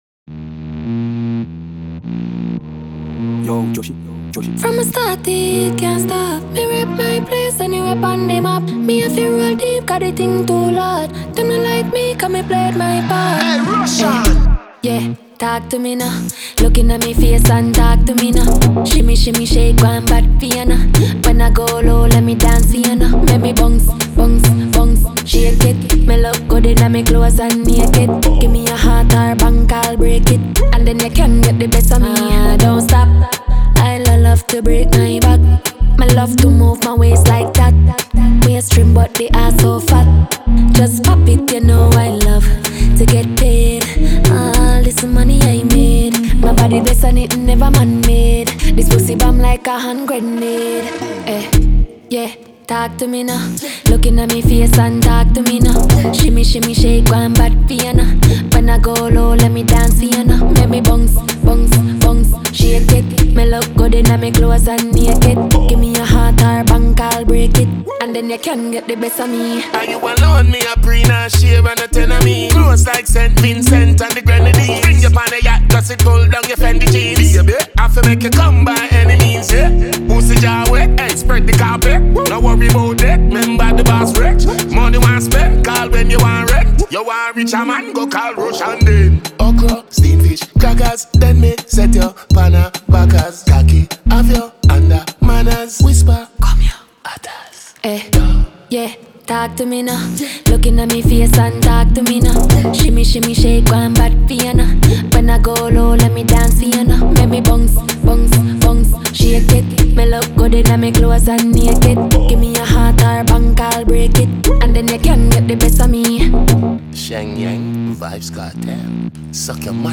Трек размещён в разделе Зарубежная музыка / Регги.